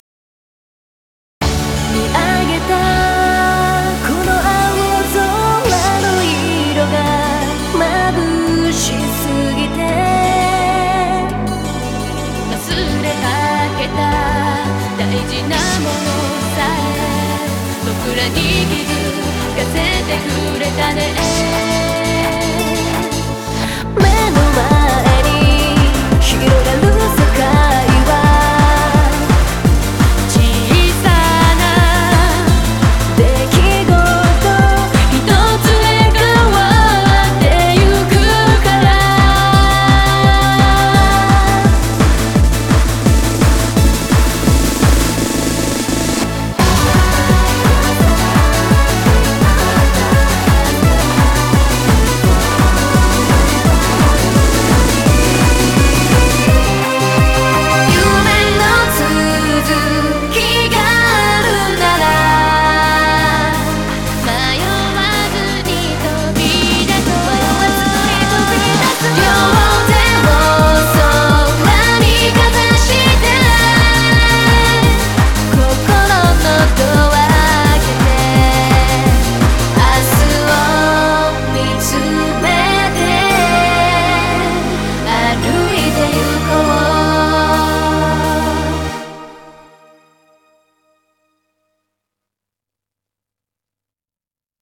BPM170
Audio QualityPerfect (Low Quality)